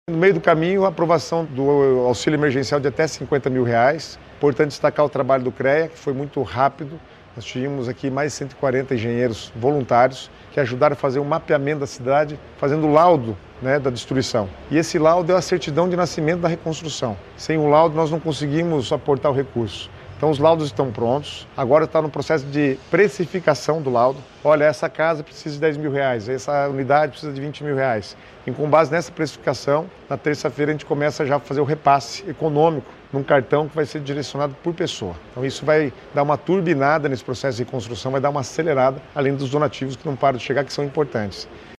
Sonora do secretário das Cidades, Guto Silva, sobre a conclusão das vistorias nos imóveis em Rio Bonito do Iguaçu